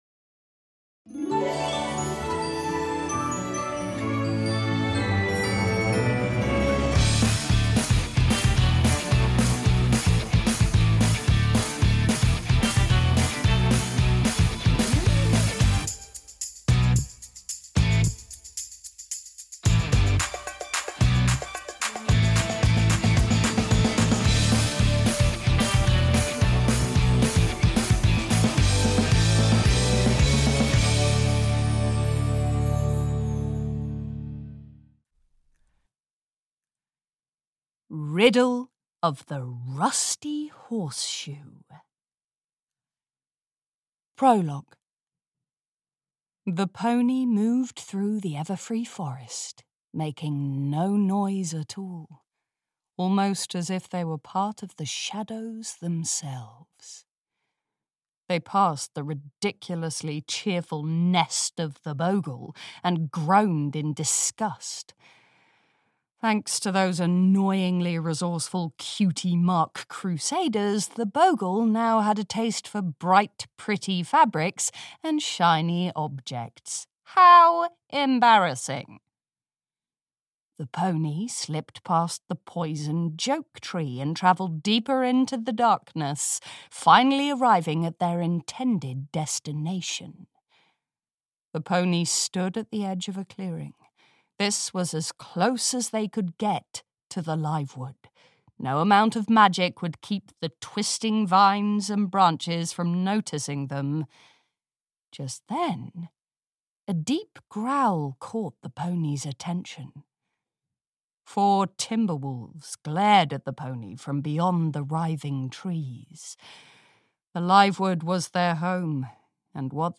My Little Pony: Ponyville Mysteries: Riddle of the Rusty Horseshoe (EN) audiokniha
Ukázka z knihy